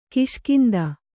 ♪ kiṣkinda